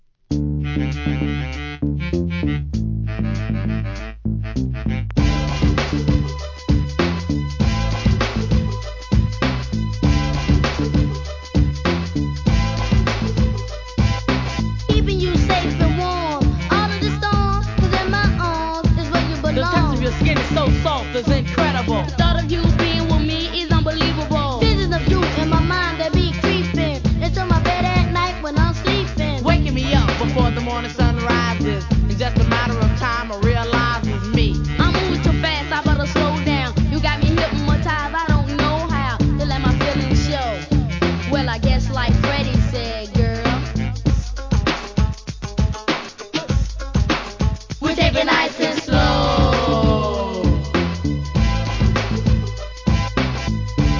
HIP HOP/R&B
1990年のKIDS RAP!!